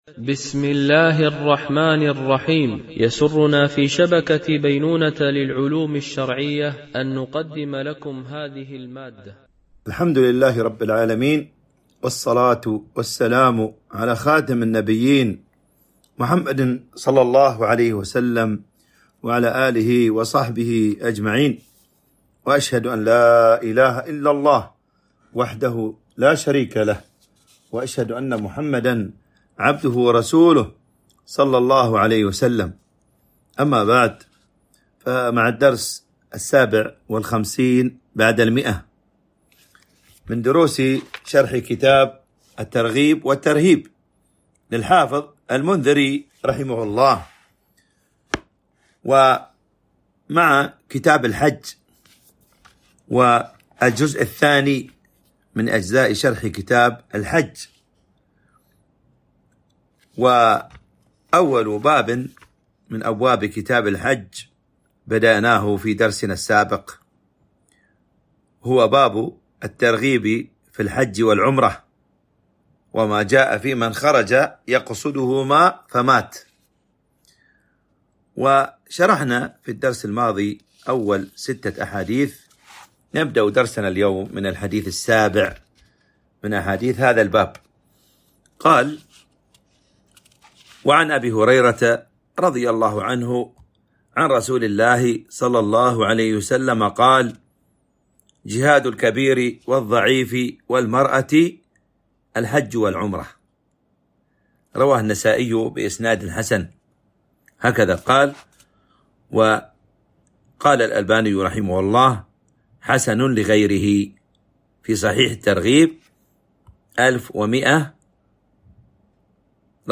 شرح كتاب الترغيب والترهيب - الدرس 157 ( كتاب الحج - الجزء الثاني - باب الترغيب في الحج والعمرة )